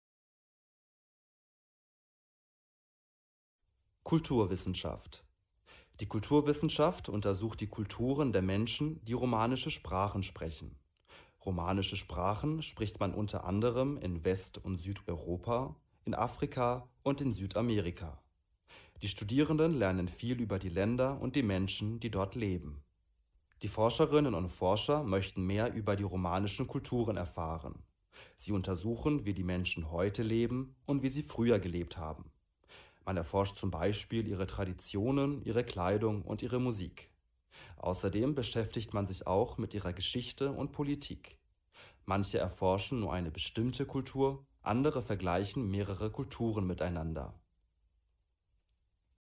Hörversion der Seite.